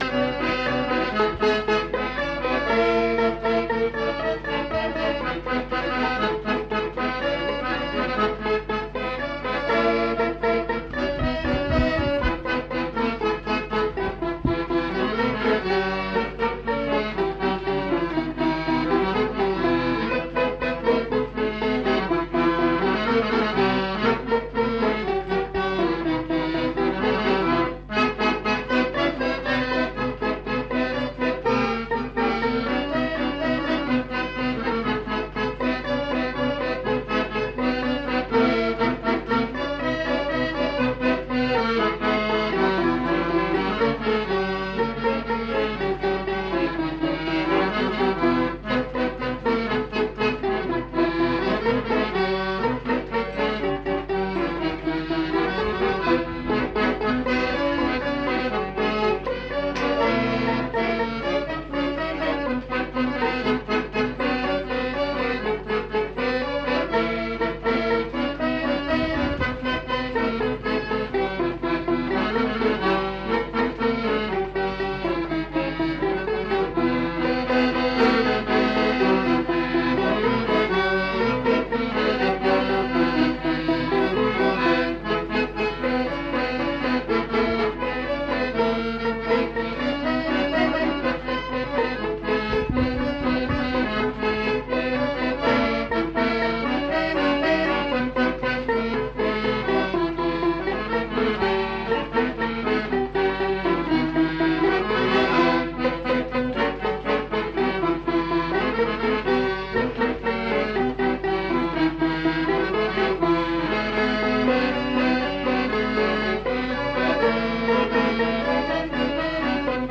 Répertoire de danses des Petites-Landes interprété au violon et à l'accordéon chromatique
enquêtes sonores
Polka